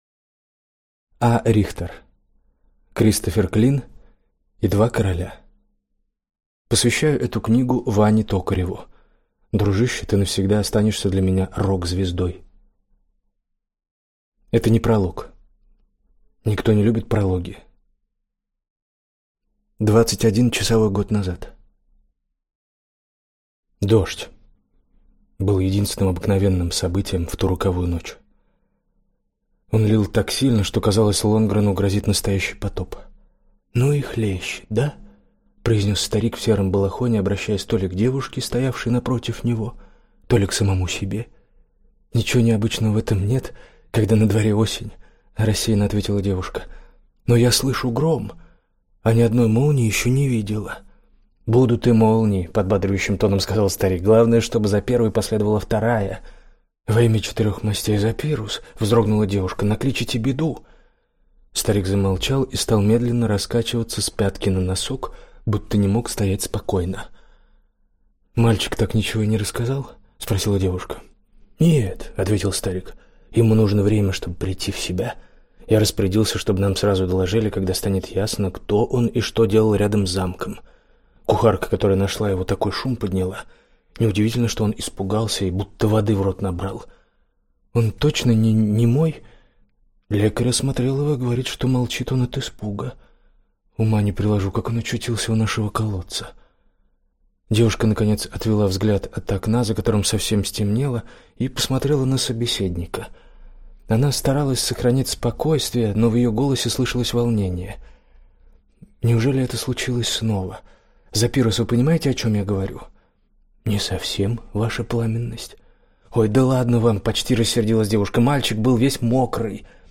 Аудиокнига Кристофер Клин и два короля | Библиотека аудиокниг